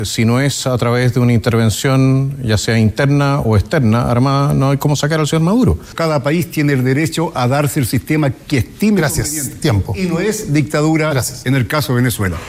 Los dardos cruzados que dejó primer debate presidencial televisivo